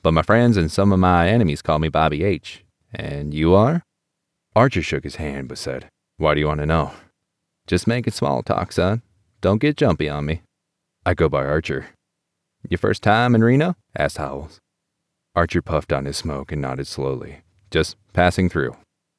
My numbers look amazing after these settings and I am going to upload a renewed sample that has used these settings per your recommendation.
but it’s not been applied: if you look at the spectrum it still has the notches …
If I hadn’t heard the original, which has audible hiss, I would not be able to tell an expander had been used.